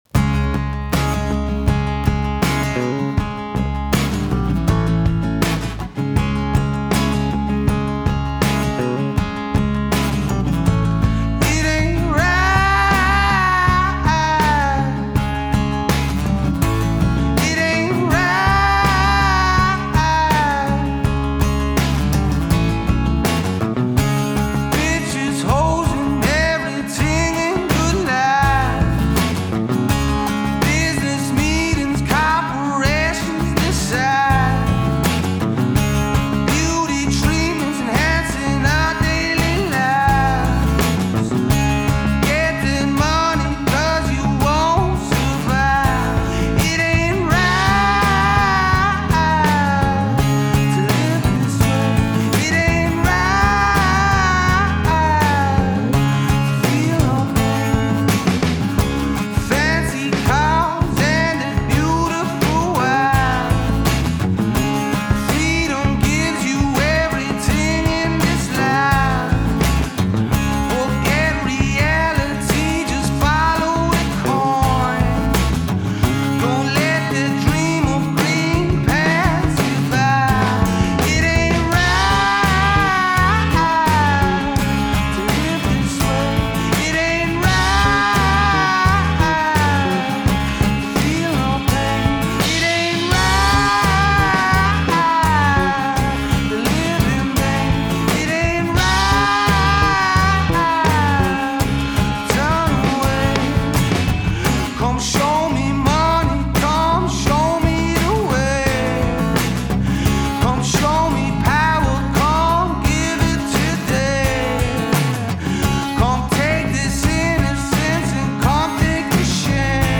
I added Bass and Drums